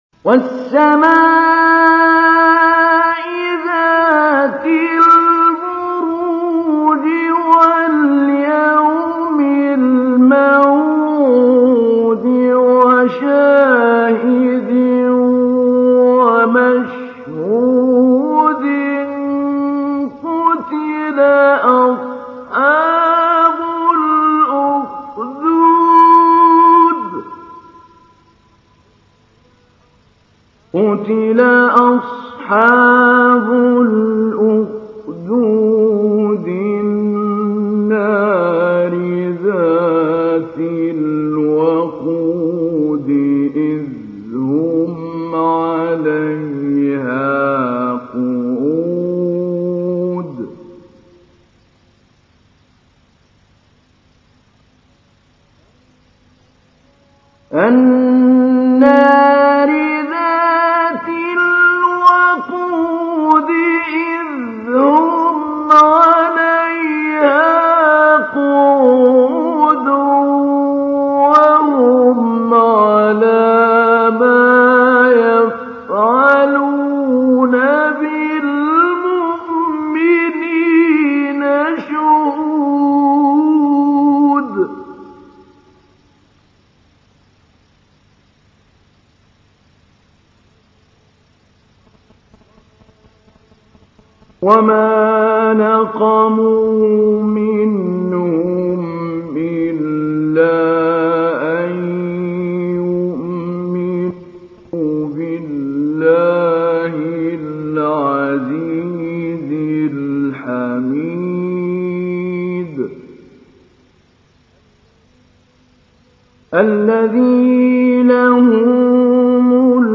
تحميل : 85. سورة البروج / القارئ محمود علي البنا / القرآن الكريم / موقع يا حسين